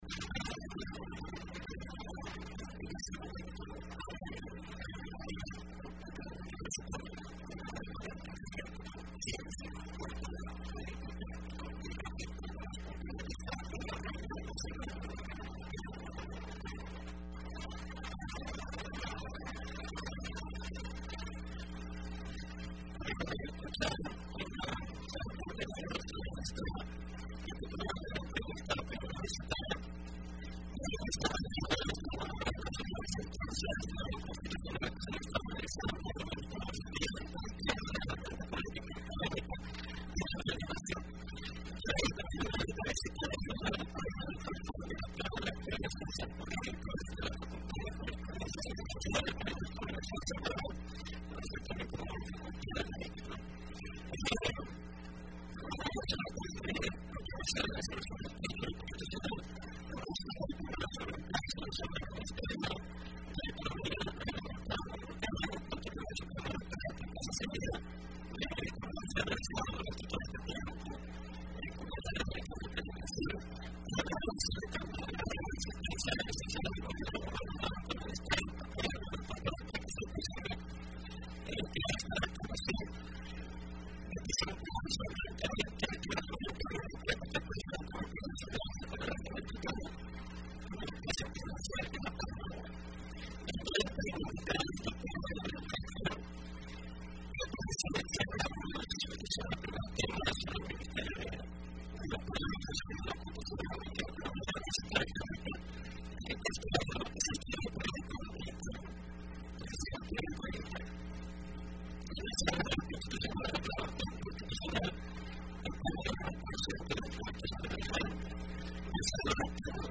Entrevista Opinión Universitaria (29 de abril de 2015): Sentencias Sala de lo Constitucional de El Salvador